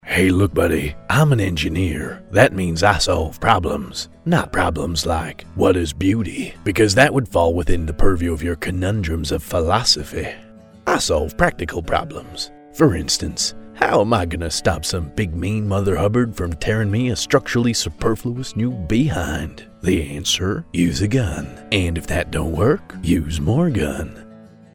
southern us | natural
Southern_US_Demo.mp3